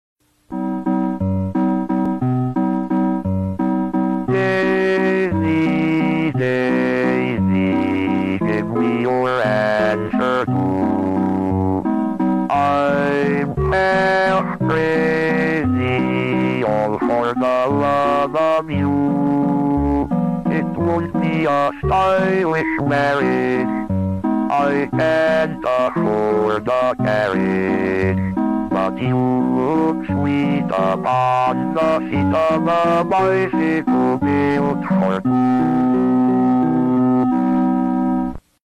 In 1961, an IBM 7094 at Bell Labs made history 🎶 It became the first computer to sing by performing the song Daisy Bell, originally composed by Harry Dacre in 1892. This early demonstration of computer speech synthesis was so iconic, it later inspired the HAL 9000’s rendition in 2001: A Space Odyssey (1968).